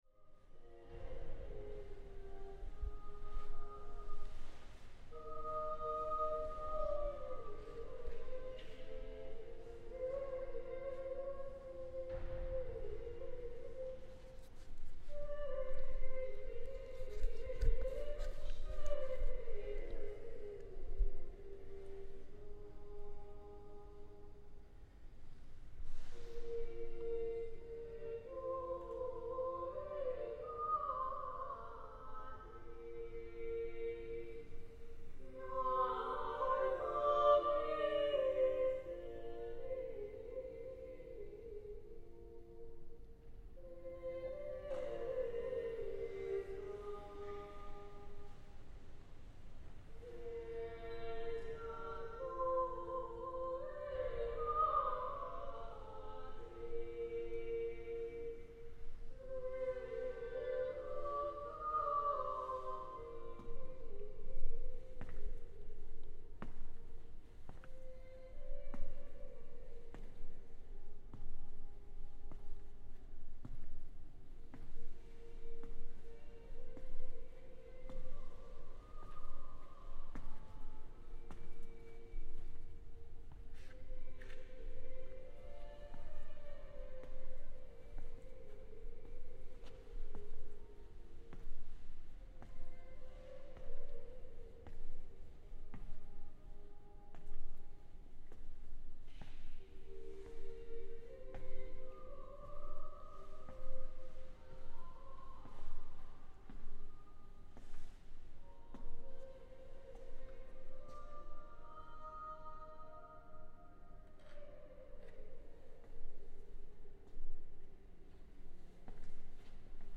This recording captures the piped angelic singing inside the church that gives it a special atmosphere, as well as the sounds of walking around the space alone.